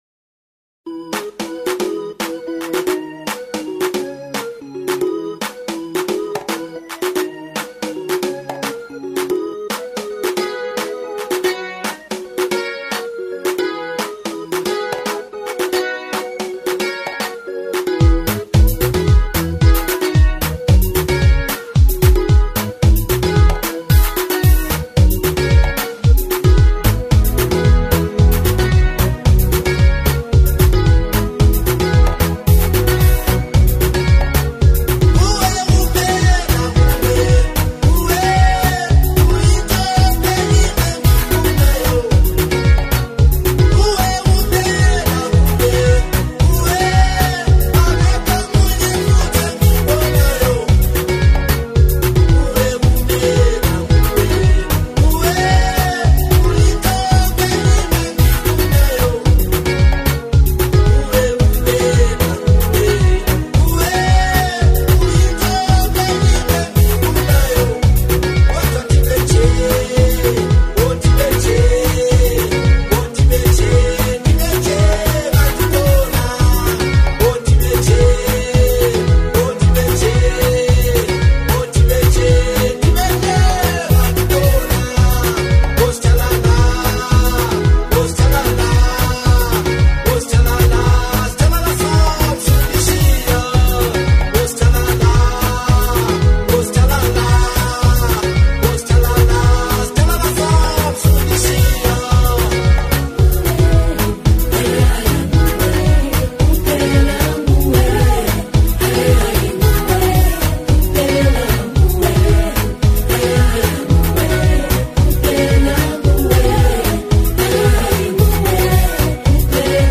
professional studio recording.
high energy